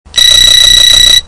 At some toll plazas, tags will emit the following sound if the account balance is low – top up as soon as possible
rms-trill.mp3